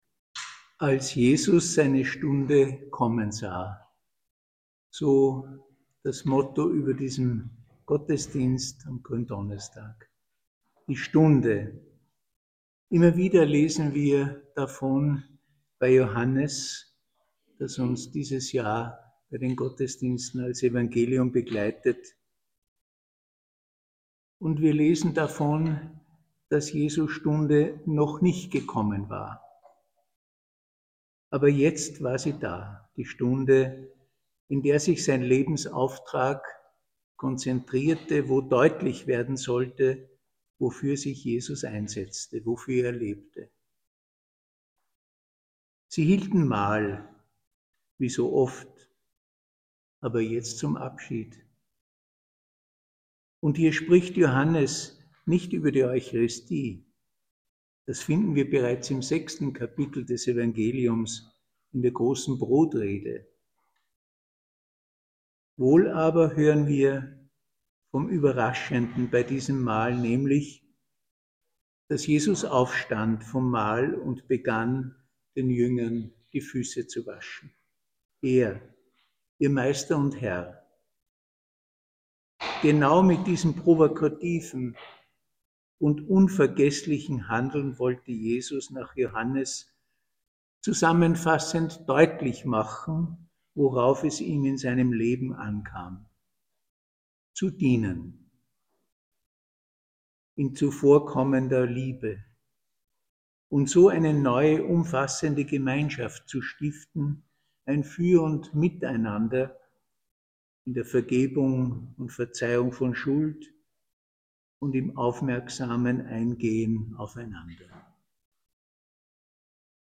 Worte zur Schrift
Ruprechtskirche